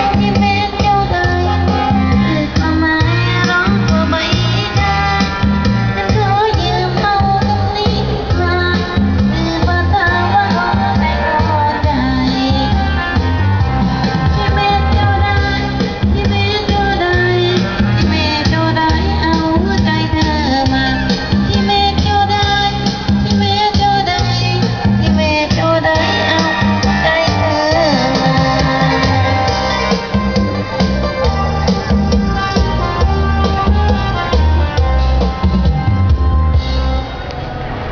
Die Musik auf dem Markt wurde von diesen DJs beigesteuert.
(Musikprobe)
Another market in the village of Salaya.